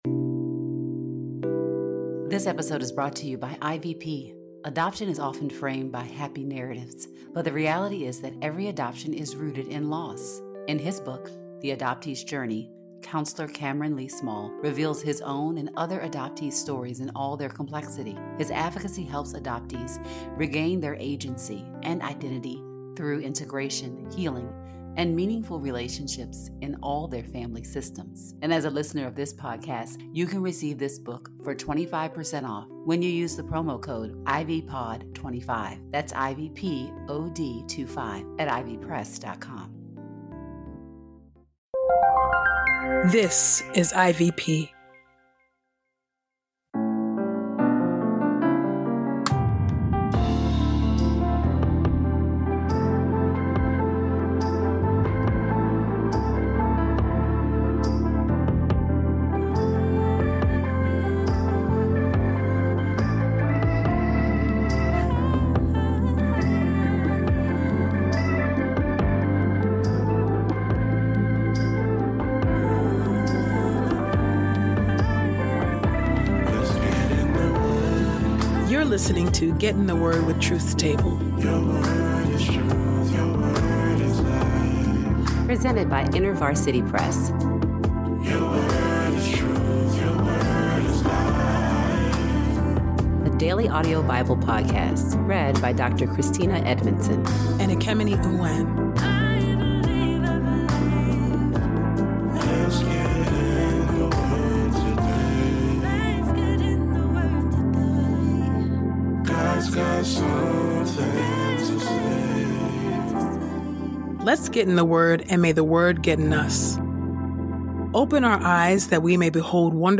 a daily Bible podcast